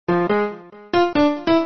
piano nē 84
piano84.mp3